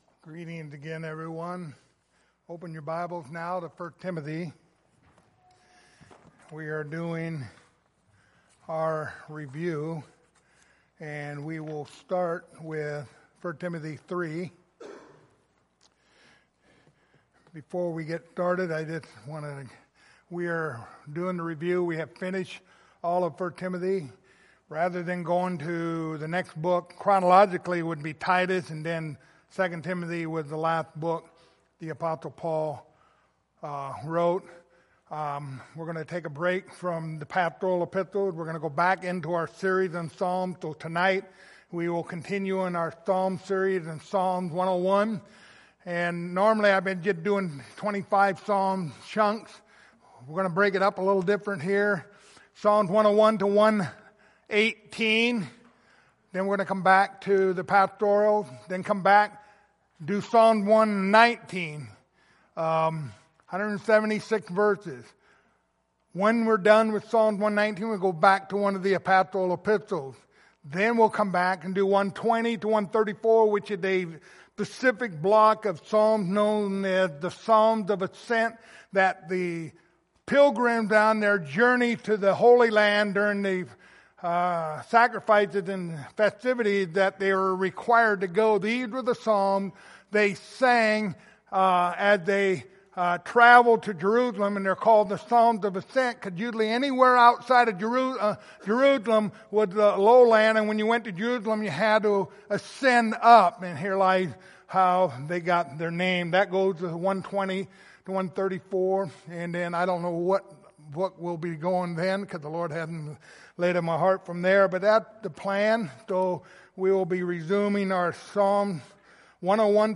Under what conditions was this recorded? Passage: 1 Timothy 3:15-16 Service Type: Sunday Morning Topics